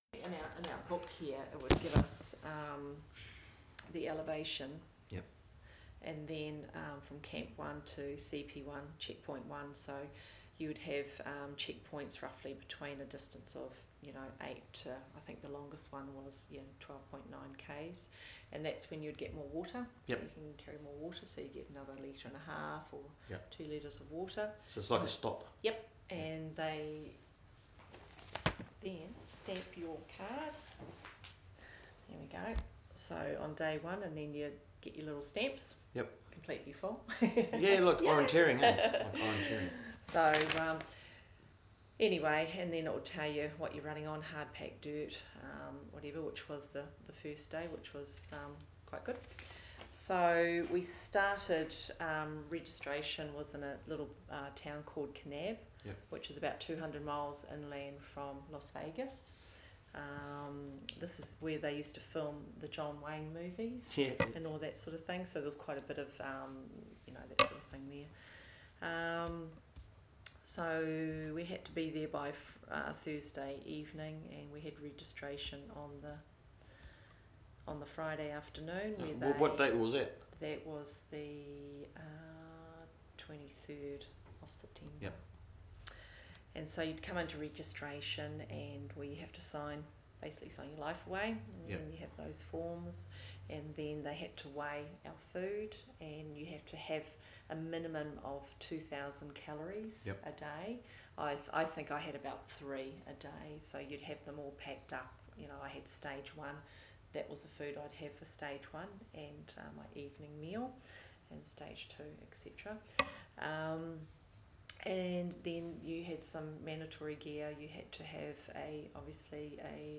We interview a local runner that competed in a grueling ultramarathon of the Grand Canyon.